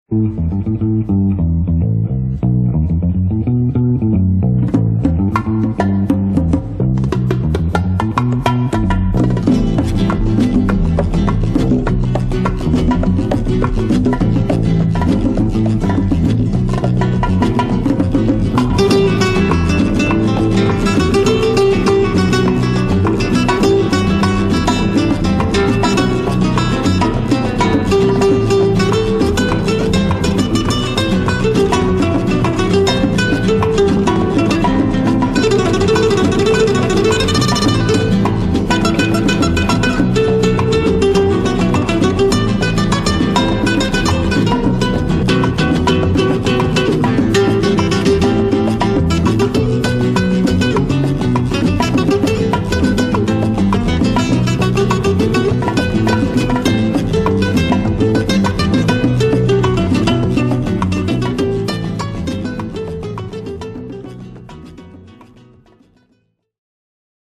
RUMBA (